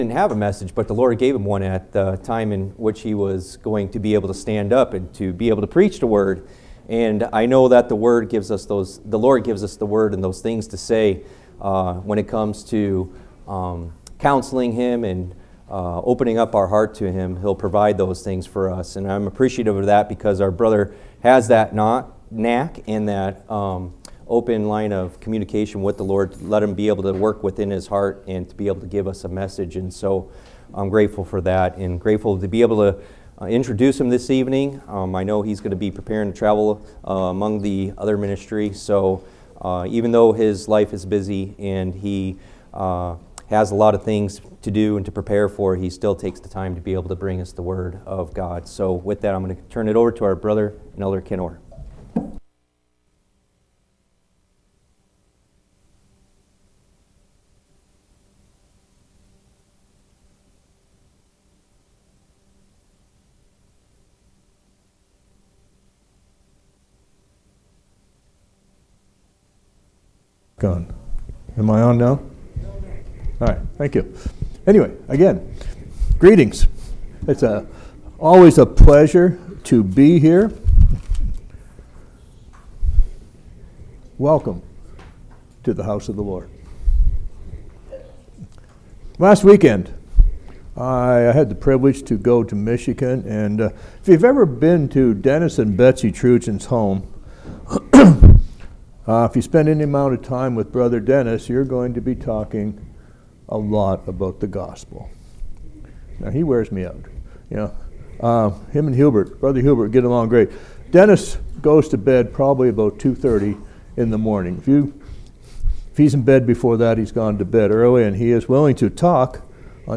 8/4/2019 Location: Phoenix Local Event